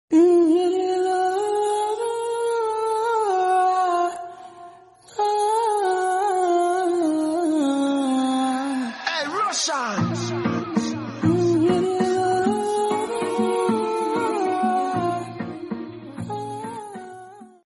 8D sound 🦇🔥 | Use sound effects free download